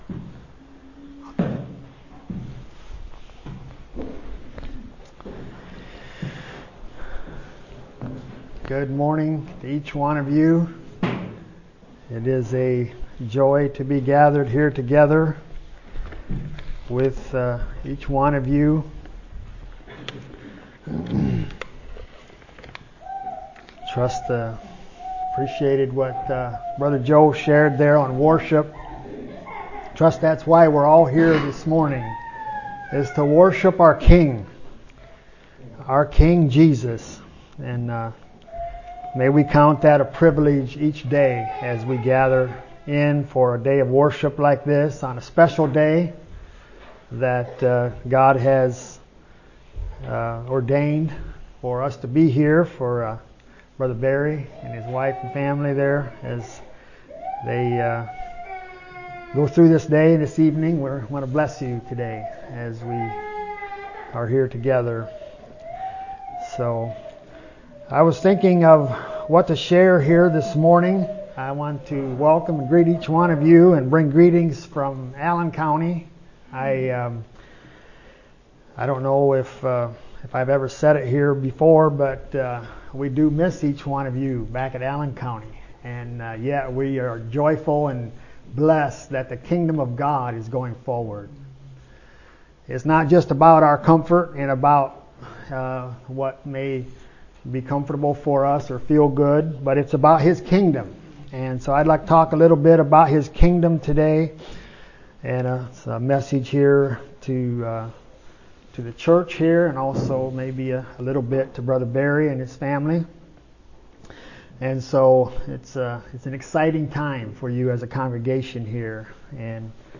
A message from the series "2025 Messages."